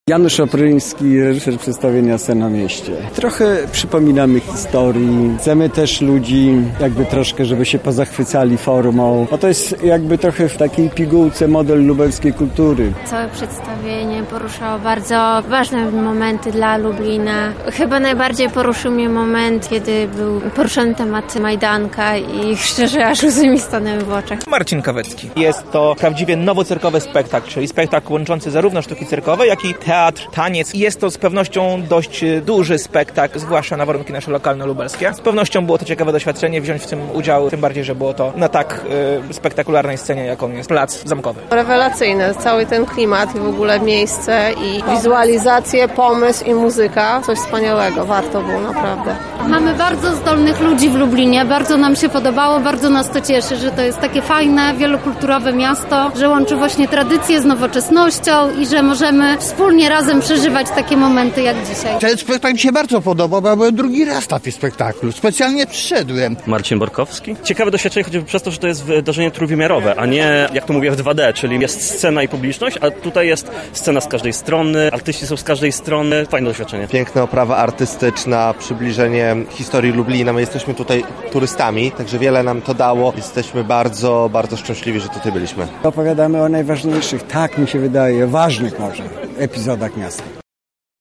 Po raz drugi w Lublinie odbył się spektakl „Sen o mieście”.
Na miejscu był nasz reporter: